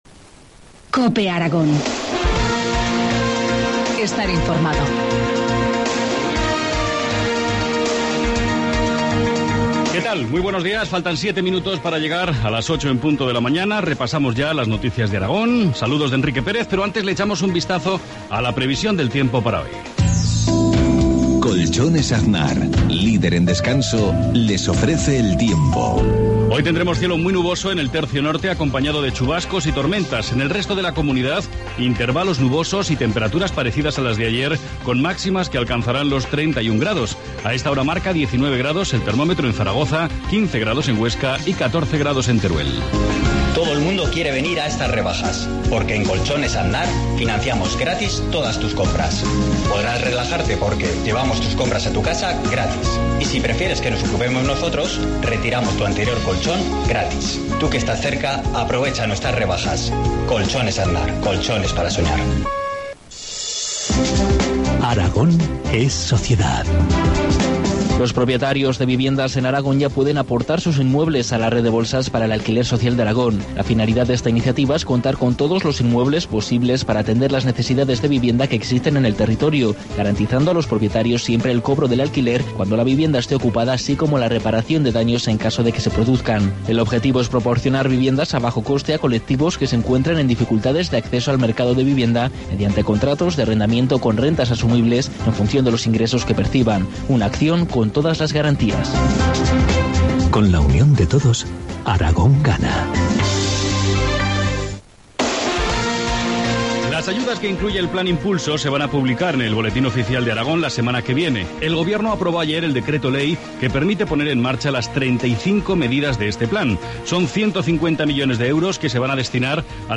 Informativo matinal, Viernes 6 septiembre, 2013, 7,53 horas